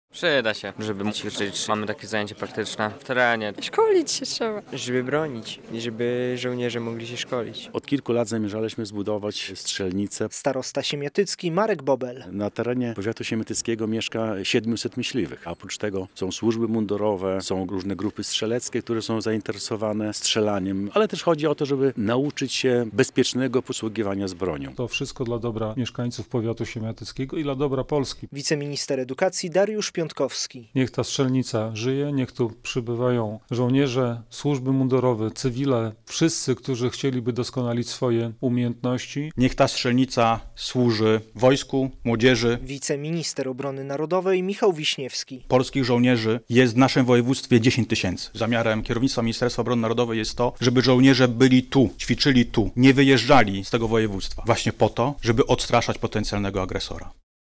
W uroczystości otwarcia strzelnicy wzięli udział posłowie, ministrowie i samorządowcy.